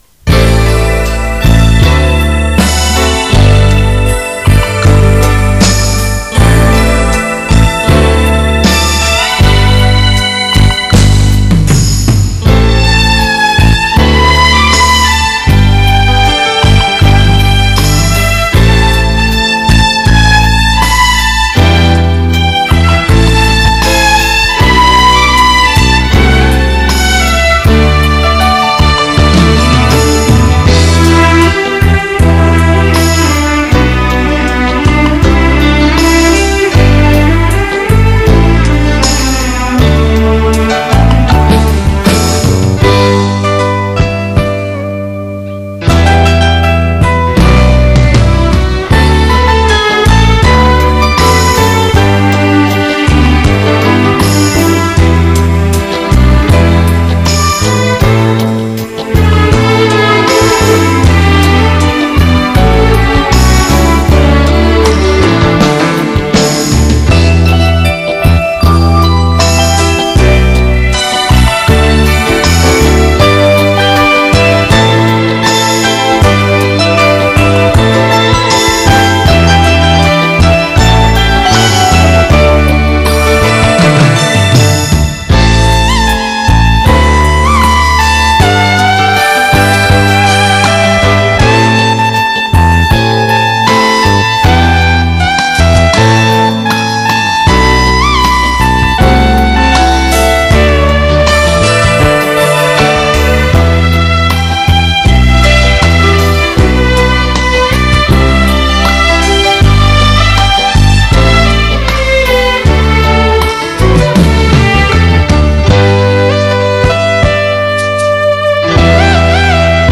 （环绕立体声）
（慢四
运用强烈的摇滚节奏与奔放的架子鼓点
本曲低音部分较突出 显得比较爆 如若不喜欢Bass 请把你的音响低音关小即可